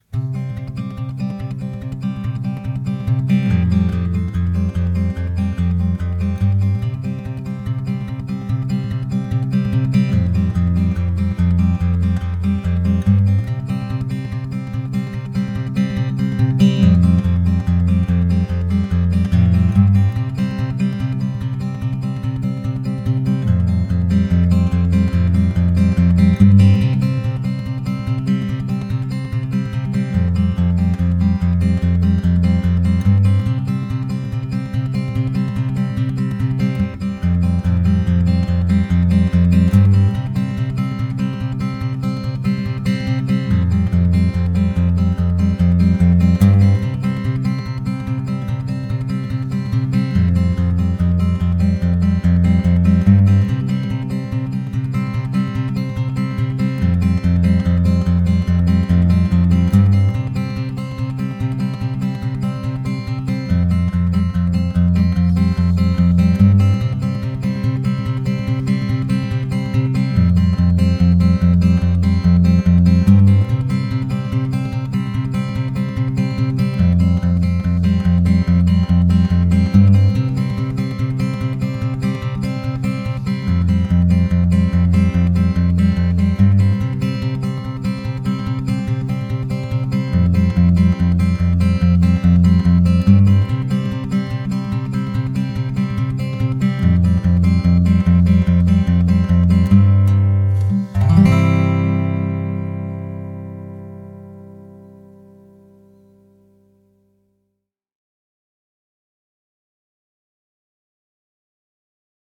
Cdur